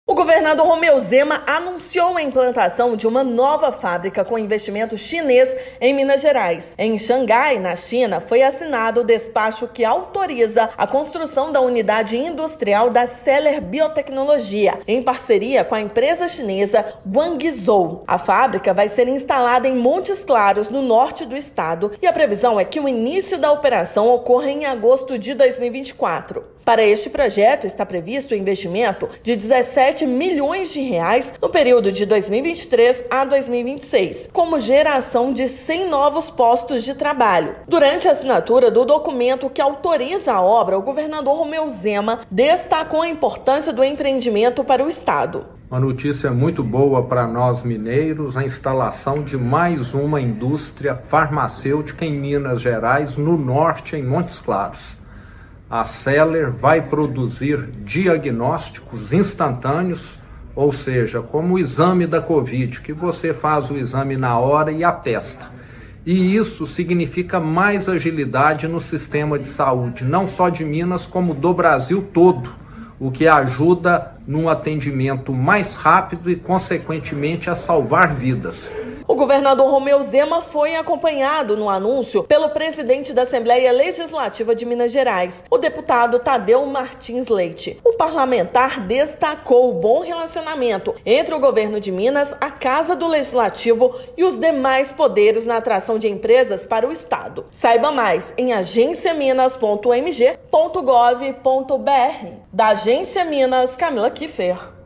Projeto, que será desenvolvido em Montes Claros, é fruto de parceria entre empresa mineira de biotecnologia e companhia chinesa para produção de exames com alta tecnologia que garantem diagnósticos rápidos e confiáveis. Ouça matéria de rádio.